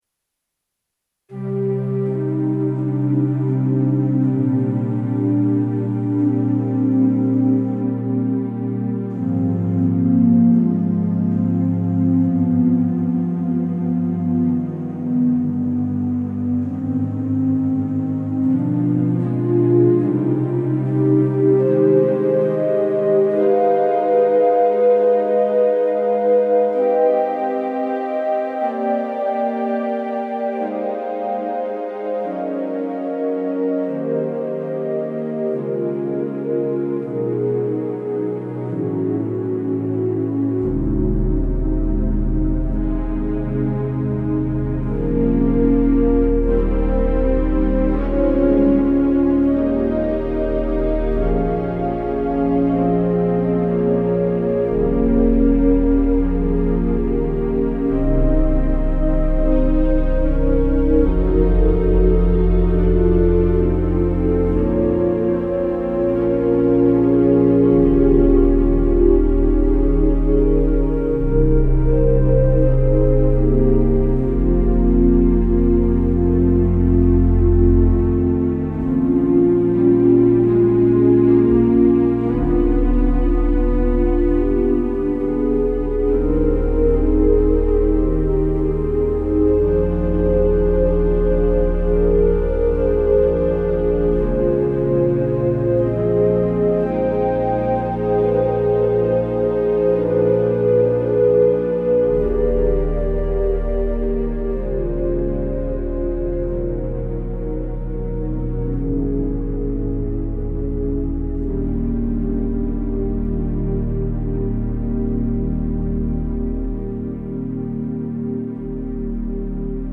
Kimball Organ • Basilica of St. Josaphat - Undercroft • Milwaukee, WI